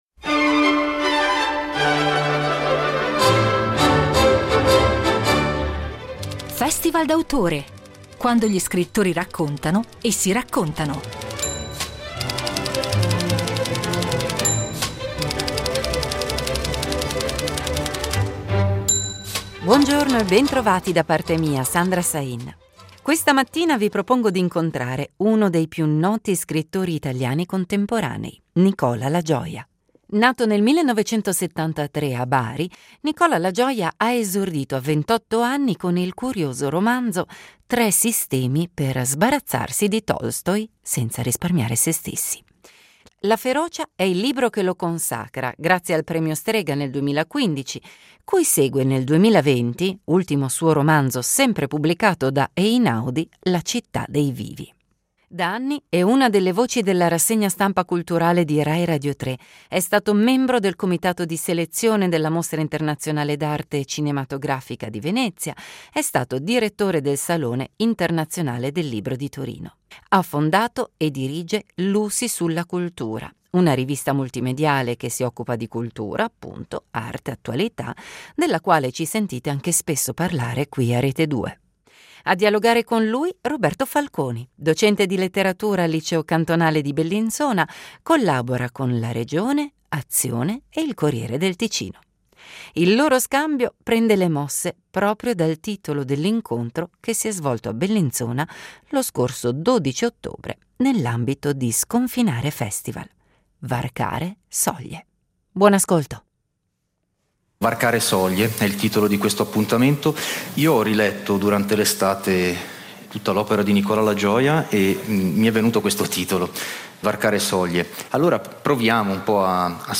Incontro a Sconfinare Festival
La ricostruzione attenta del caso è anche strumento per restituire un ritratto tragico di una generazione confusa e di una capitale corrotta. Ospite di Sconfinare Festival nell’ottobre 2024, ripercorre la sua carriera con slancio e generosità in un incontro intenso che muove costantemente tra letteratura e mondo, parole e cose.